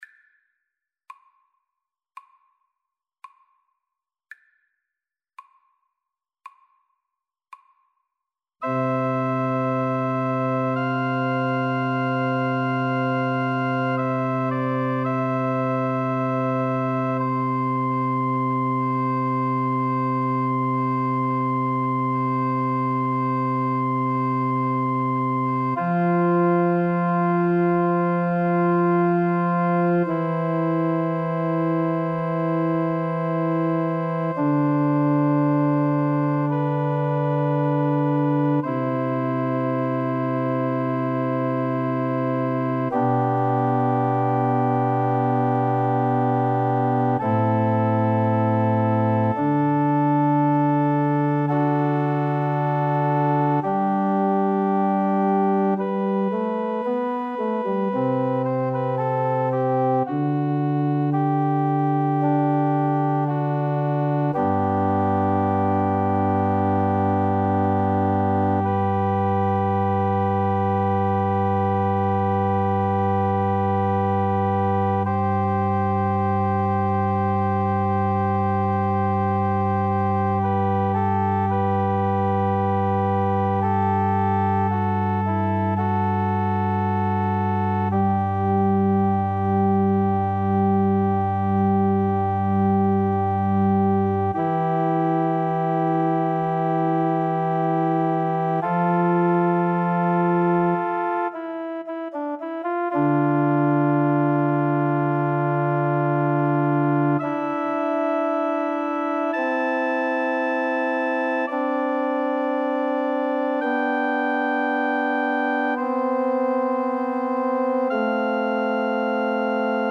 Flute
C major (Sounding Pitch) (View more C major Music for Flute )
=56 Adagio
4/4 (View more 4/4 Music)
Classical (View more Classical Flute Music)